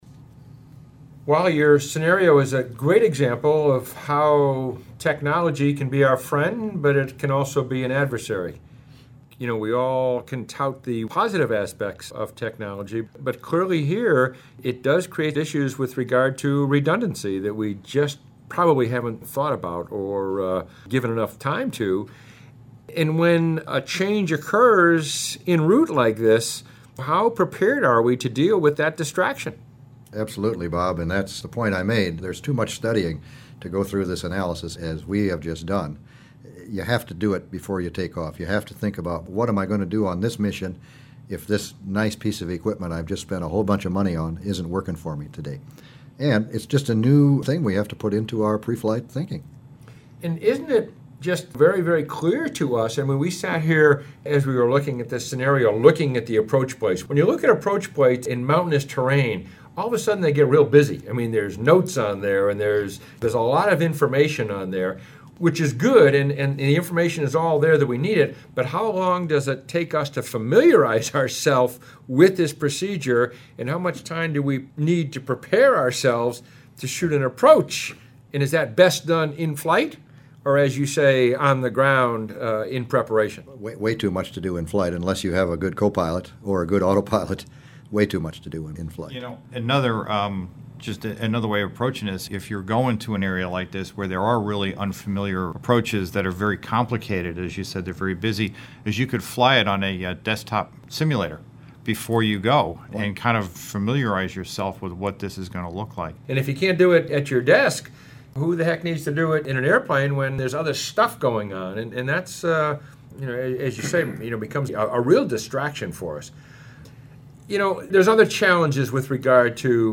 gps-loss-roundtable.mp3